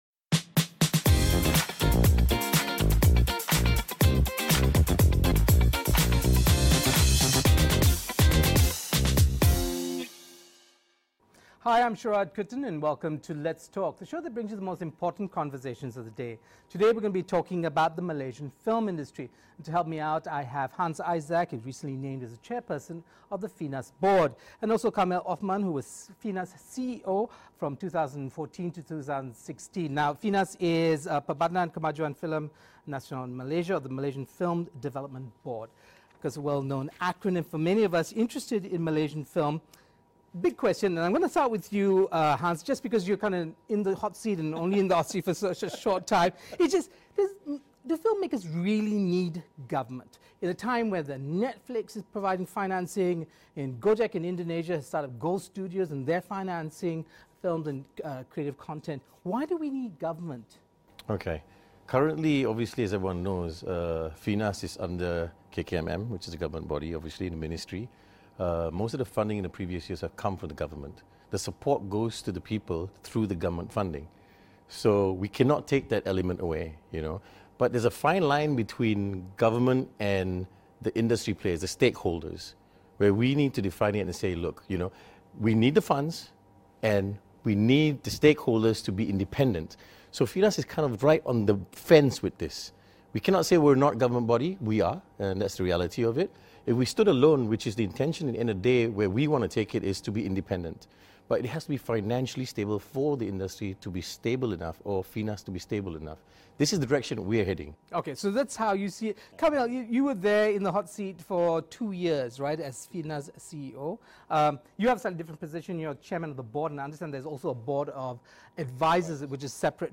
We speak to Hans Isaac (Chairperson, FINAS Board) and Kamil Othman (CEO FINAS, 2014 to 2016).